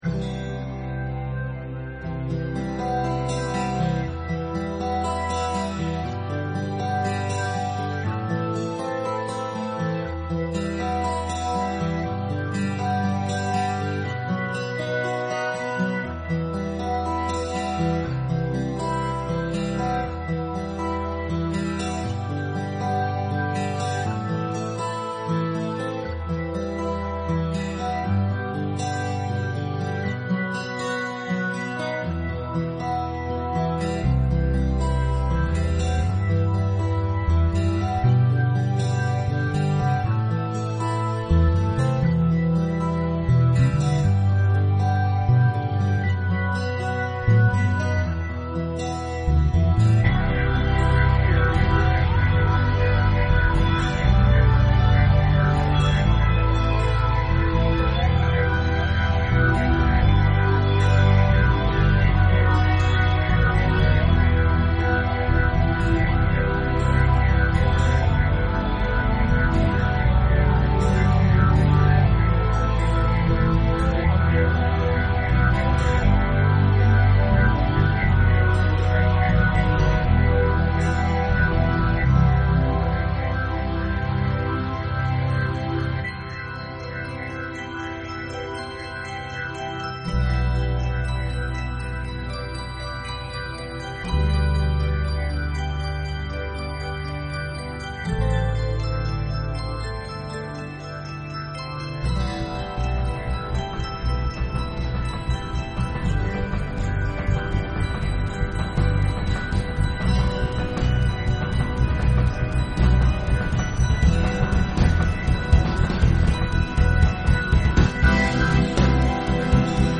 progressive rock band